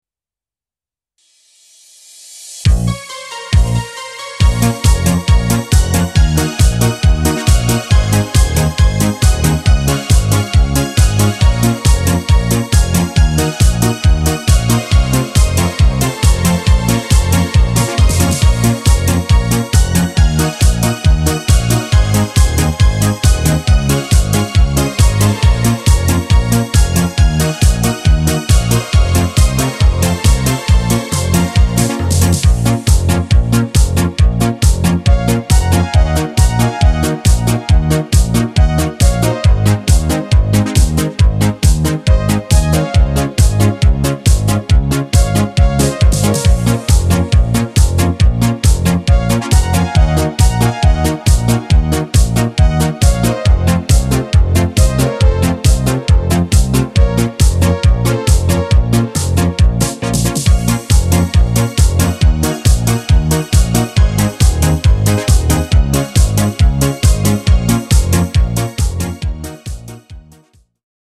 Klimat lat '90-tych cały czas na topie.
Disco Polo